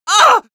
Voice file from Team Fortress 2 Spanish version.
Scout_painsevere03_es.wav